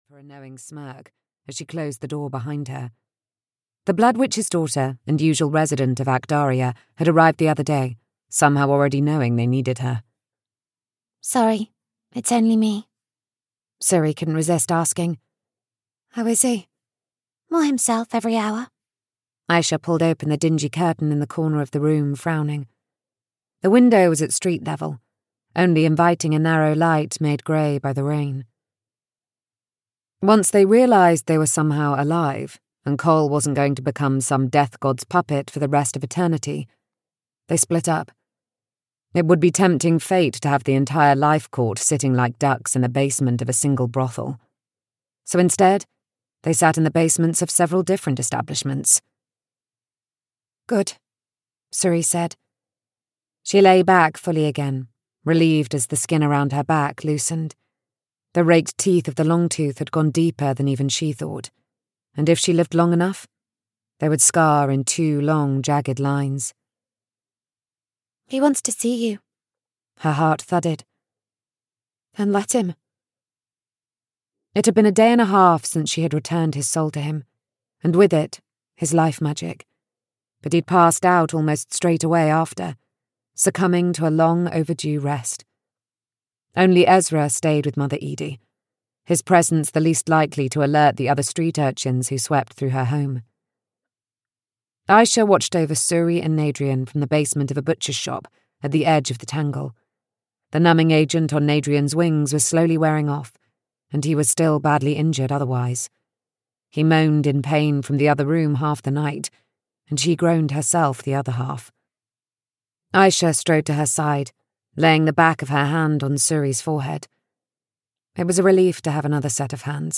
A Claiming of Souls (EN) audiokniha
Ukázka z knihy